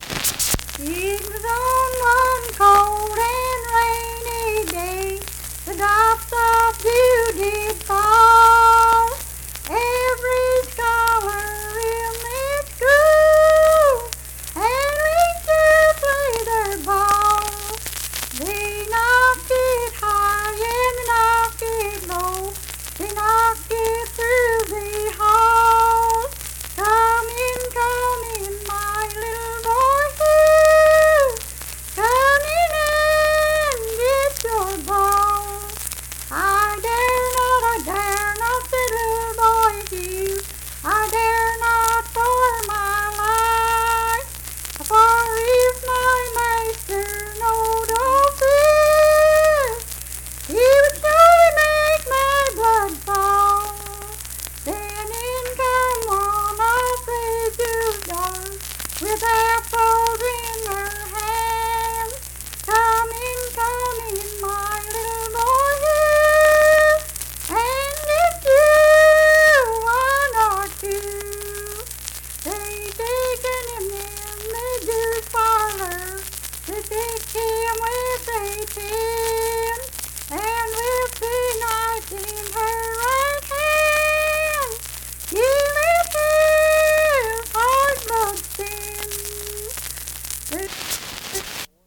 Unaccompanied vocal music performance
Verse-refrain 8(4).
Voice (sung)
Mingo County (W. Va.), Kirk (W. Va.)